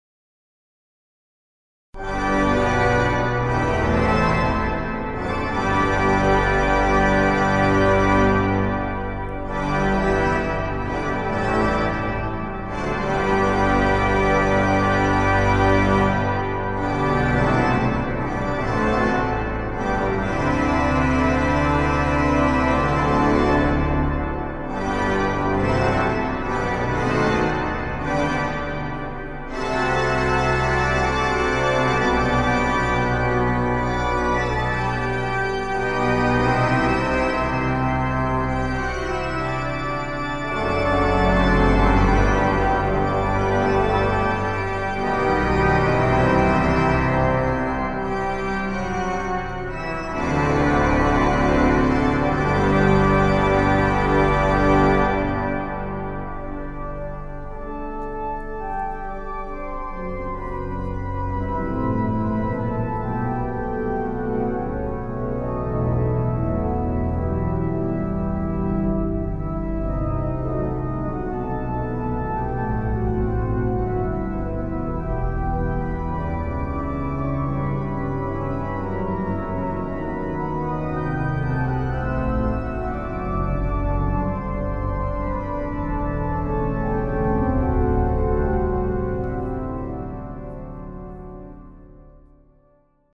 Basilica del Sacro Cuore di Cristo Re
Concerto organistico in onore dei nuovi Santi Papa Giovanni XXIII e Papa Giovanni Paolo II
Entrée Pontificale in sol maggiore ( per il Giubileo Episcopale di S. S. Papa Leone XIII)